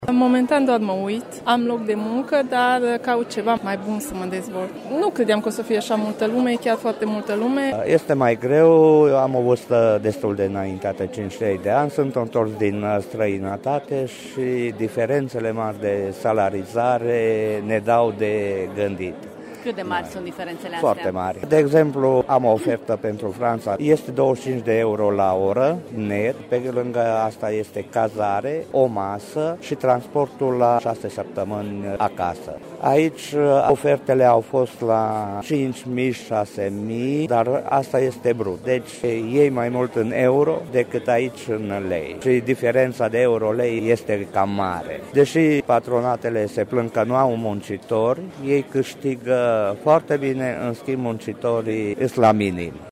Foaierul Teatrului Național, unde se desfășoară Târgul de Cariere la Târgu Mureș era înțesat de oameni încă de la deschidere. Oamenii caută oportunități noi în carieră și sunt dezamăgiți că salariile oferite sunt foarte mici: